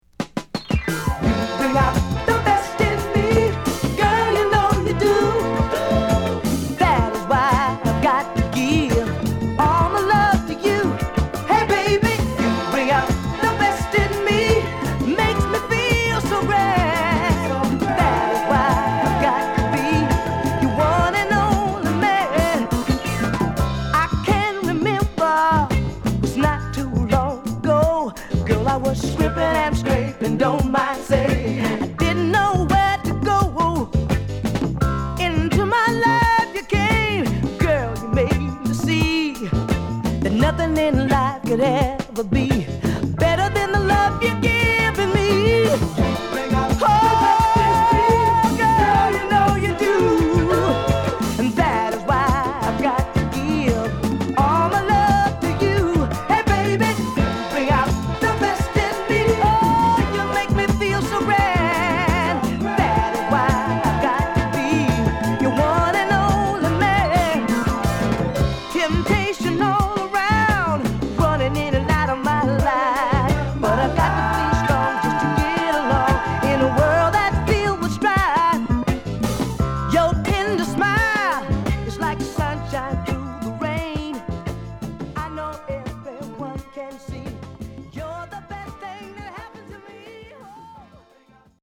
フレッシュ感漂い高揚感溢れるナイスソウルです！！